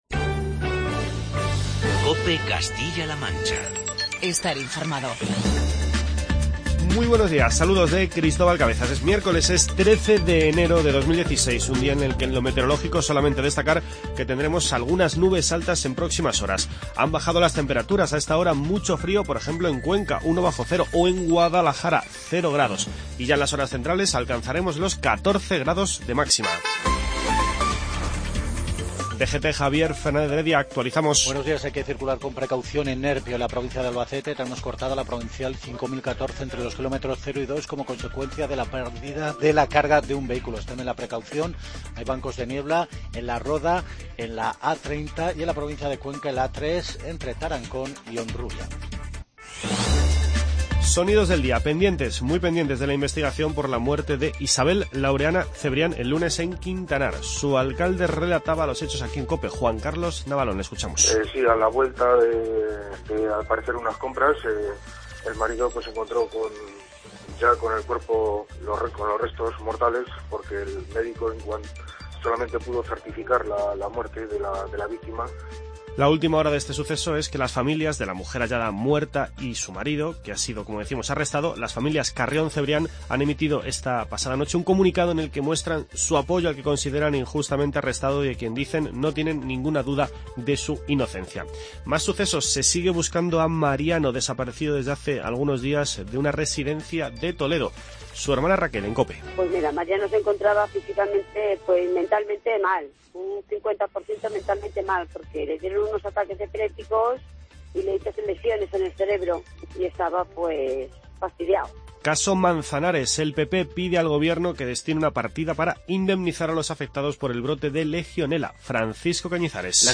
Informativo regional y provincial
Escuchamos, entre otros, el testimonio del alcalde de Quintanar de la Orden, Juan Carlos Navalón.